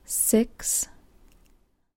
用AT 2020录制到Zoom H4.
标签： 数字说话 声带 声音
声道立体声